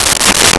Toilet Paper Dispenser 02
Toilet Paper Dispenser 02.wav